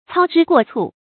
操之过蹙 cāo zhī guò cù
操之过蹙发音
成语注音 ㄘㄠ ㄓㄧ ㄍㄨㄛˋ ㄘㄨˋ